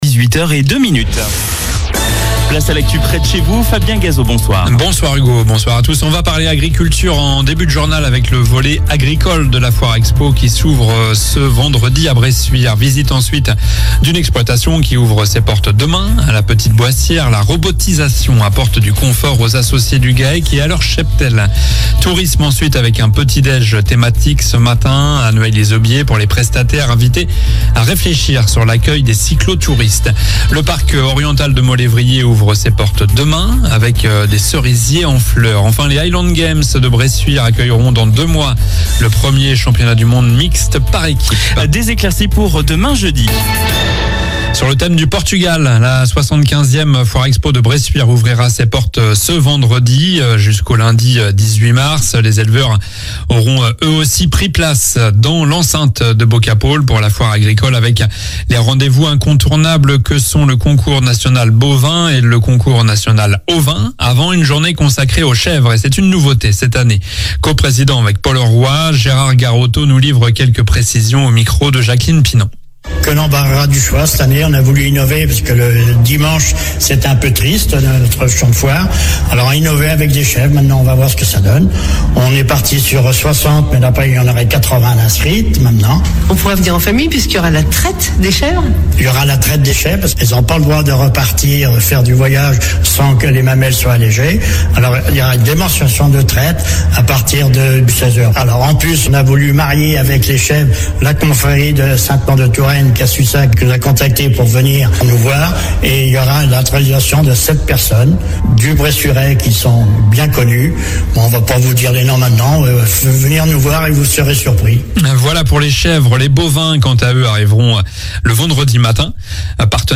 Journal du mercredi 13 mars (soir)